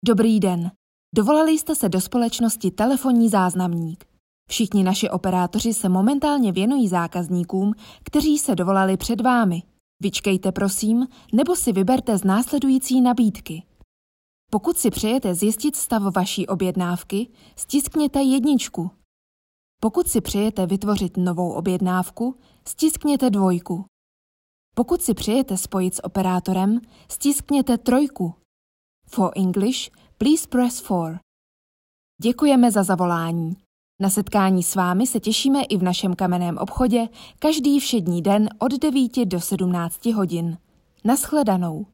Profesionální český ženský voiceover
profesionální zvuk a vysoká technická kvalita
ukazka-zaznamnik.mp3